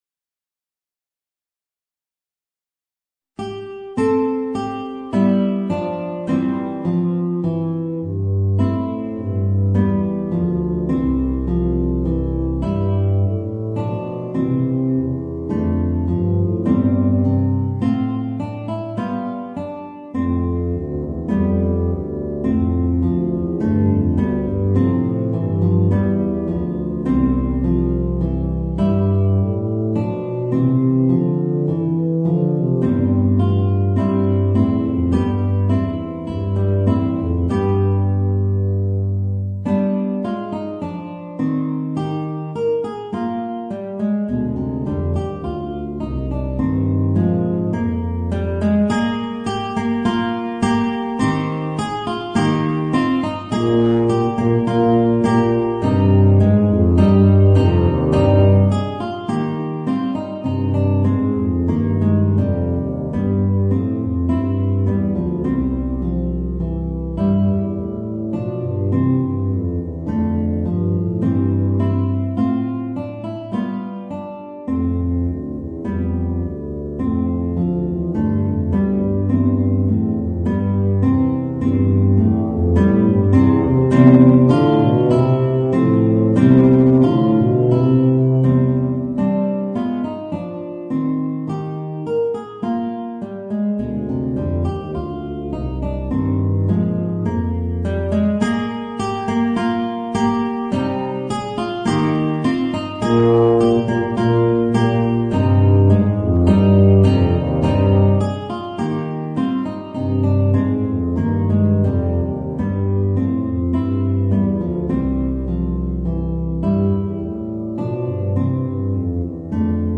Voicing: Guitar and Tuba